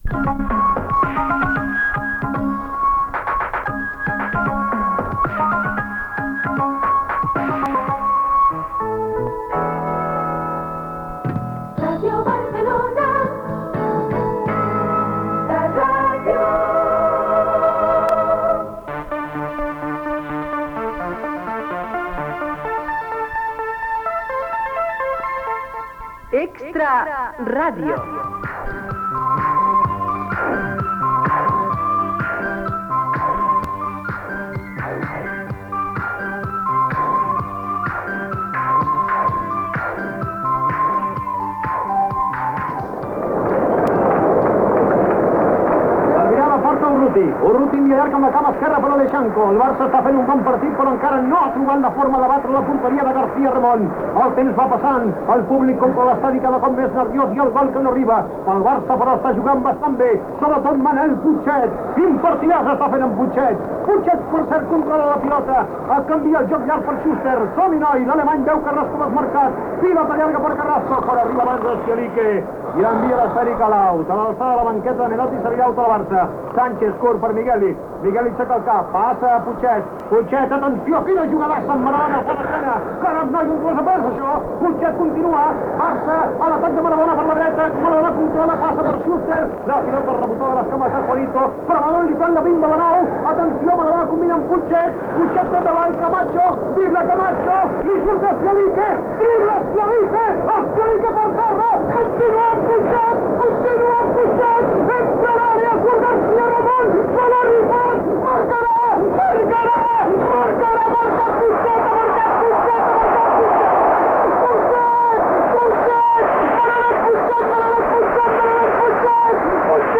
ed9d2fafbbe7da93c0f69fd1d00368cc61bba265.mp3 Títol Ràdio Barcelona Emissora Ràdio Barcelona Cadena SER Titularitat Privada estatal Nom programa Extra radio Descripció Sintonia de l'emissora, indicatiu, "De professió futbolista". Ficció sonora amb la narració de Joaquim Maria Puyal d'un gol de Putxet i diàleg entre Putxet i la seva esposa al llit. Presentació de l'espai dedicat a la feina de futbolista.